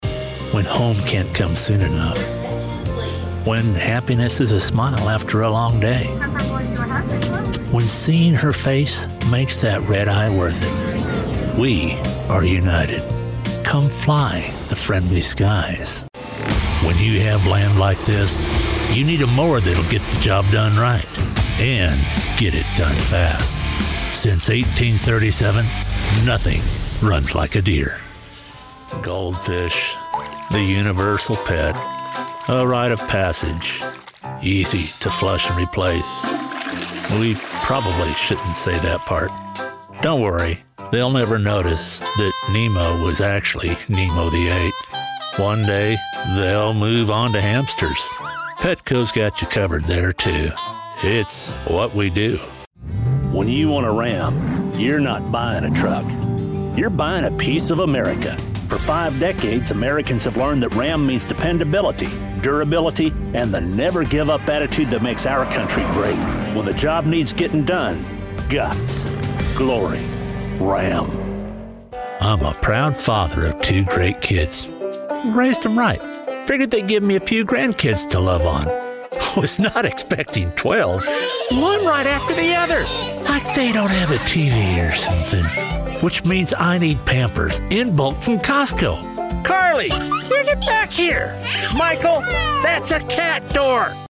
Male Voice Over Talent, Artists & Actors
Adult (30-50) | Older Sound (50+)